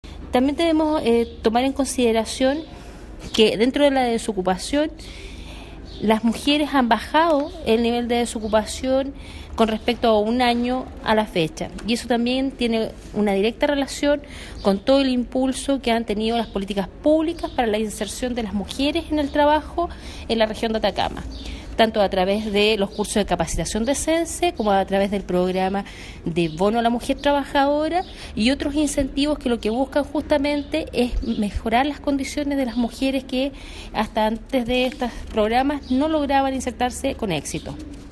Seremi-Trabajo-2.mp3